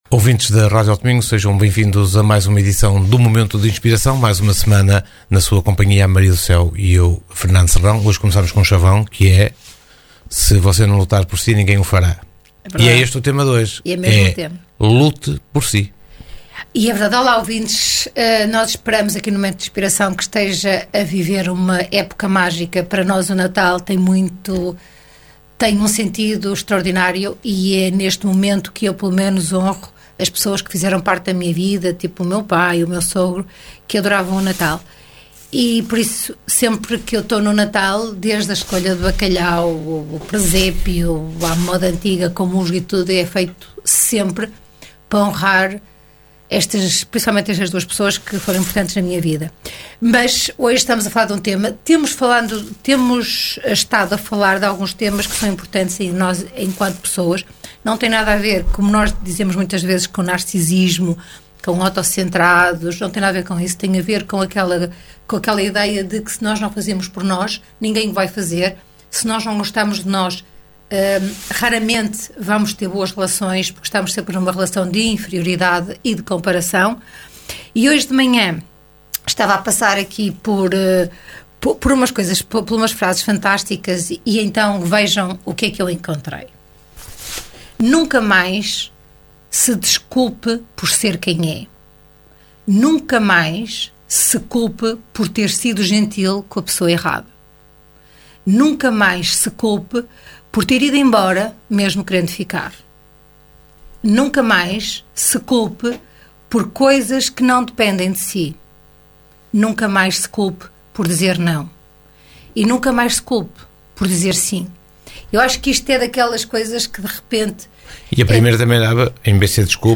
Momento de Inspiração (programa) | Segundas 22h.